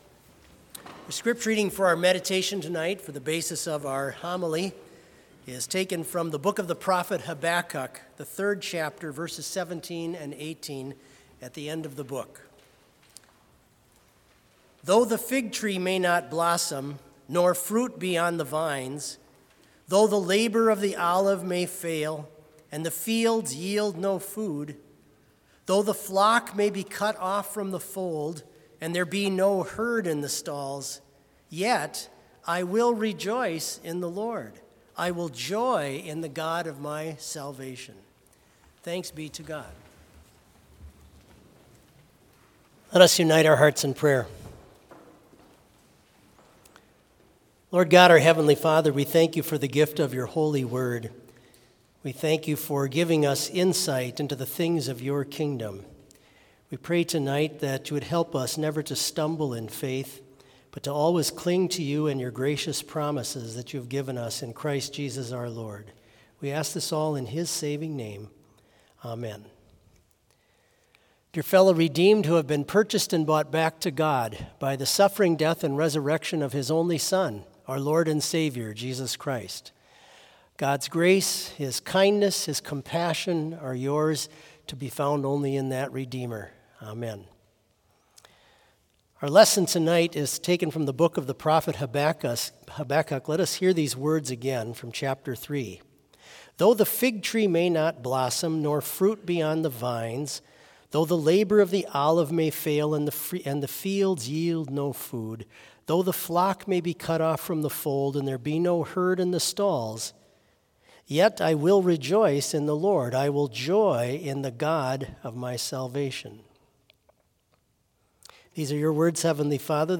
Complete service audio for Vespers - September 21, 2022
Homily
vv. 1, choir vv. 2-4, all